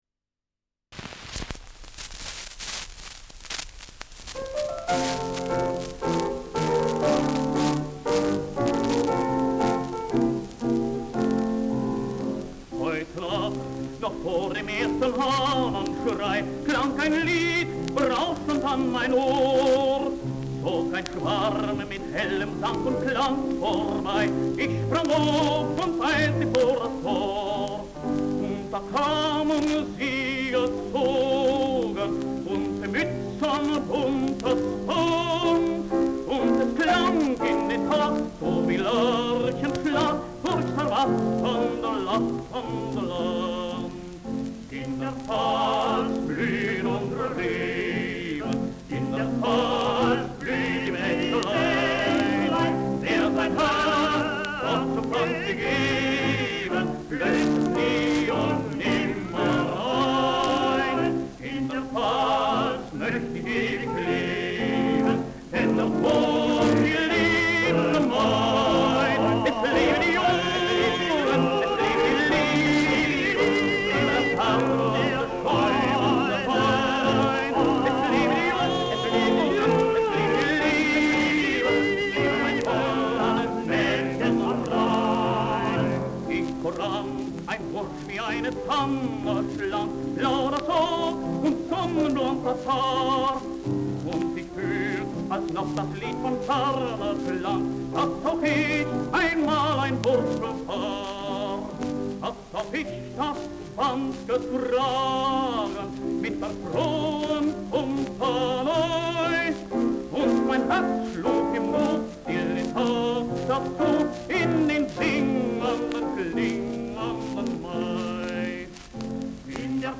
Marschlied
Schellackplatte